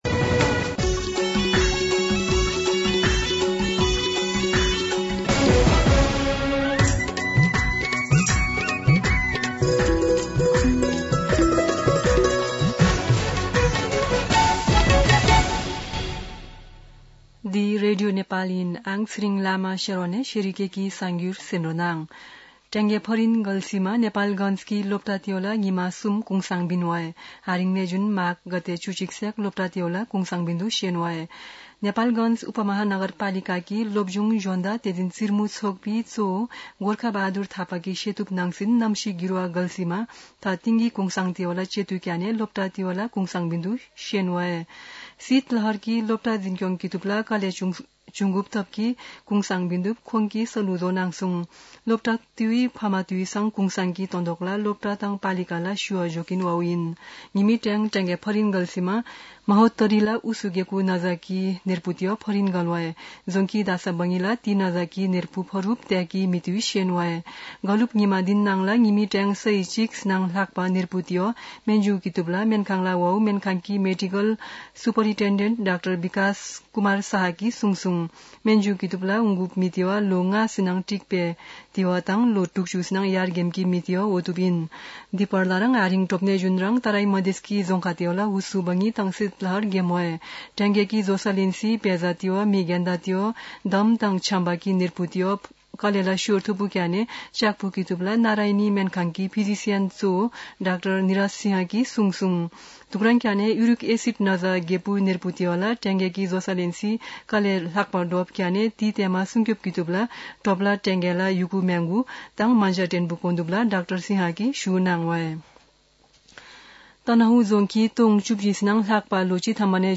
An online outlet of Nepal's national radio broadcaster
शेर्पा भाषाको समाचार : १० माघ , २०८१
Sherpa-News-10-9-1.mp3